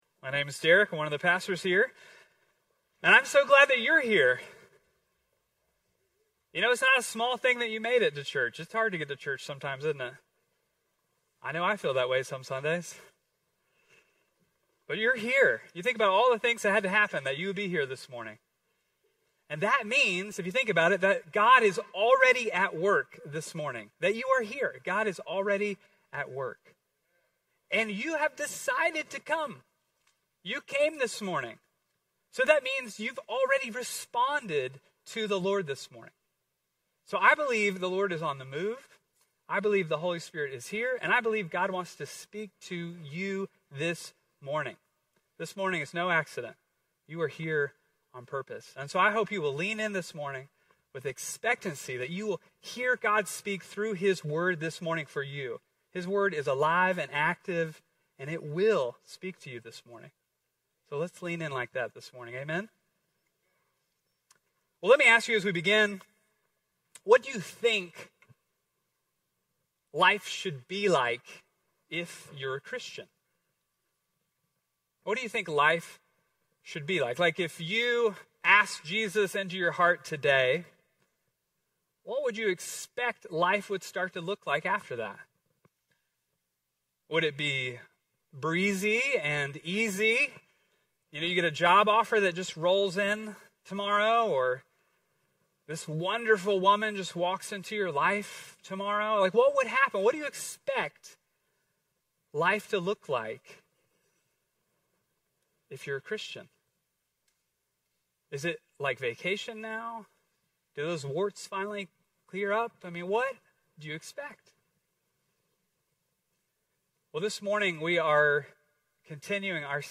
Immanuel Church - Sermons